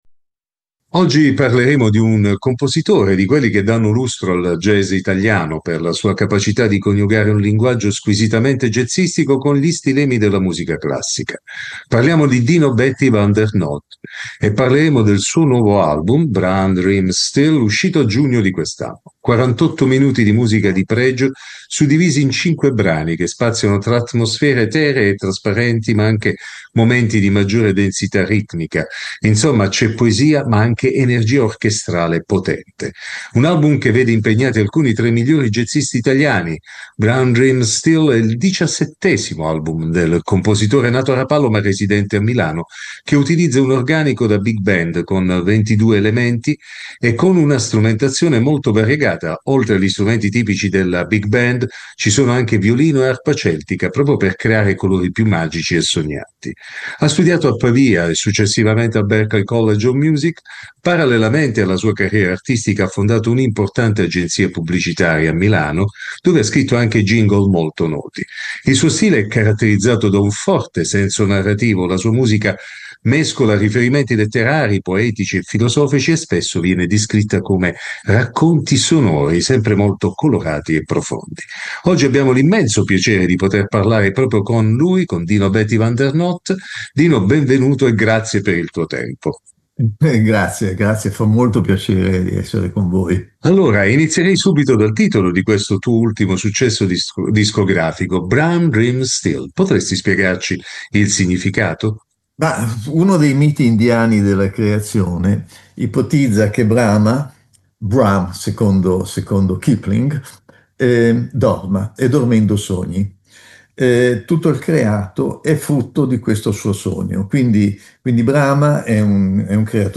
Intervista al compositore